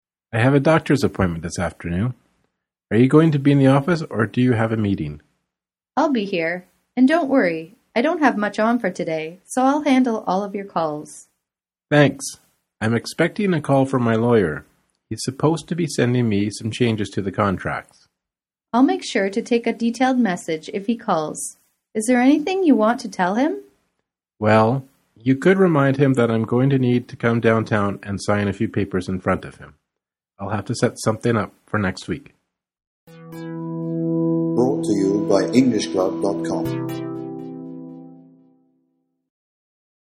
In this part of the test you will listen to a short conversation between a man and a woman.
englishclub-toeic-short-convos-2.mp3